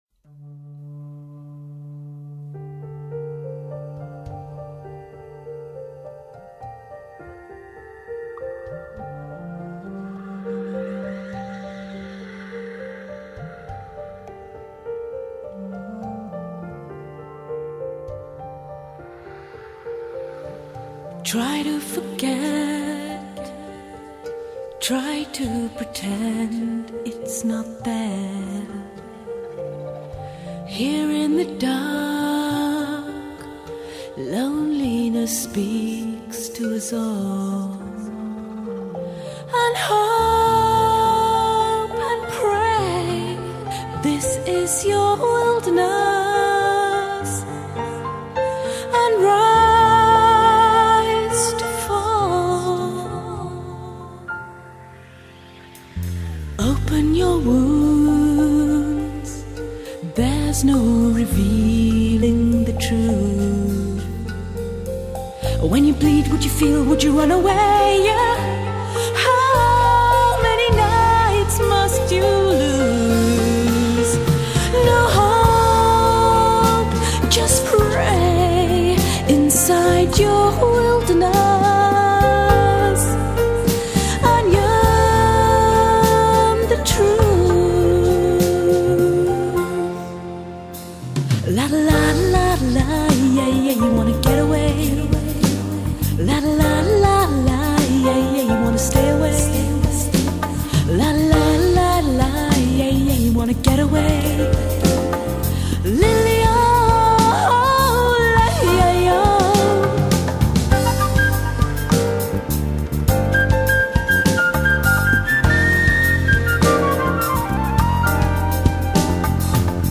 音樂類型 : 爵士
☆優雅的現代爵士風